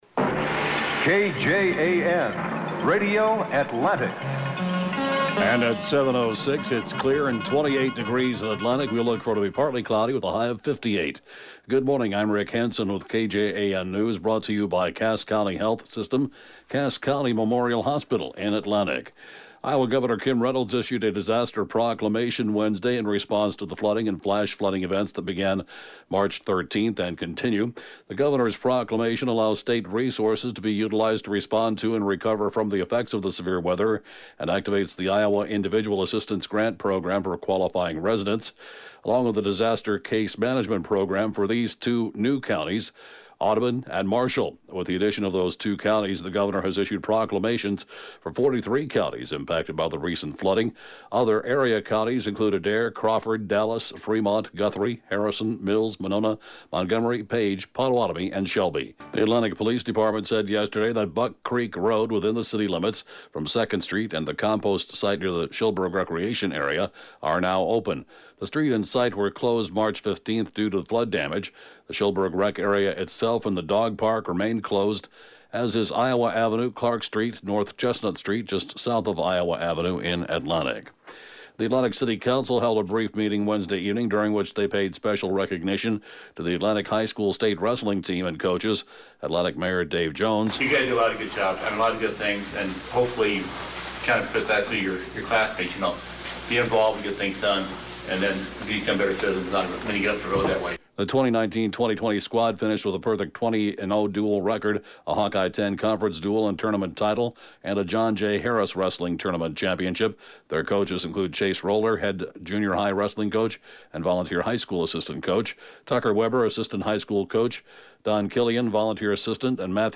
(Podcast) KJAN Morning News & Funeral report, 3/21/2019